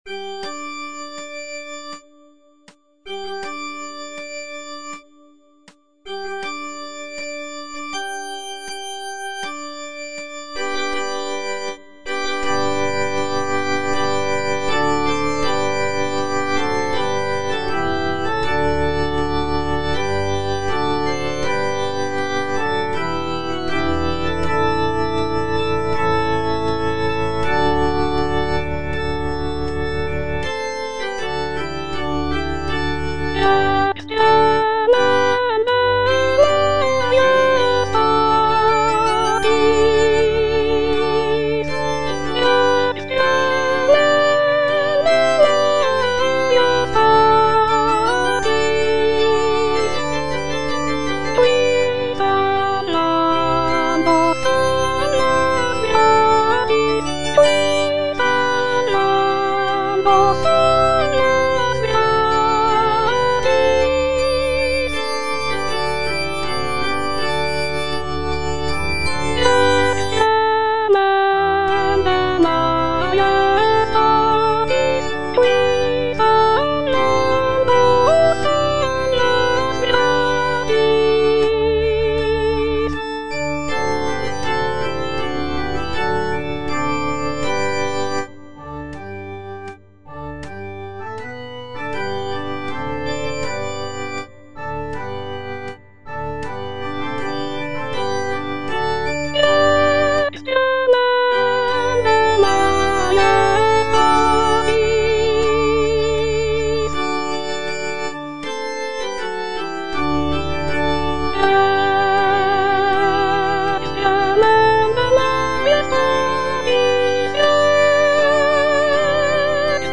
soprano II) (Voice with metronome
is a sacred choral work rooted in his Christian faith.